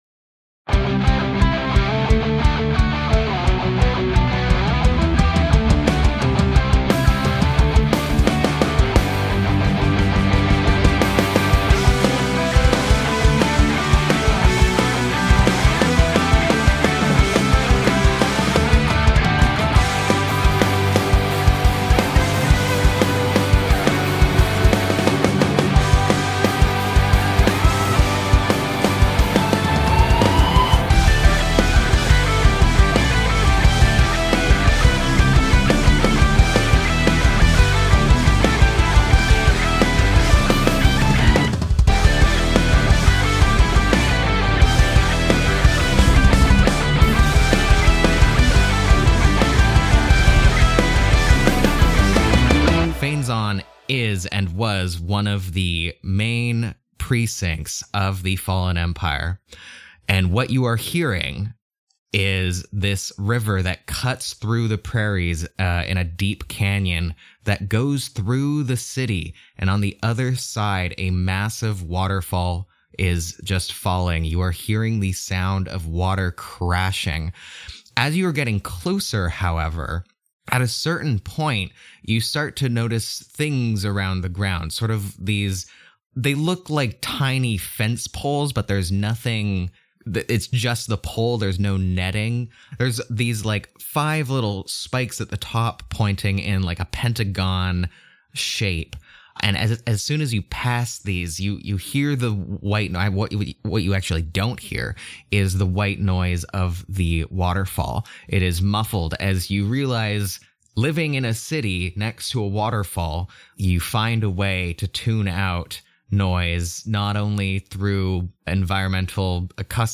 This episode contains content warnings of faked vomiting noises.